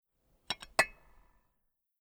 Glas
Teekanne aus dem Teeservice 5000
Deckel abnehmen
3517_Deckel_abnehmen.mp3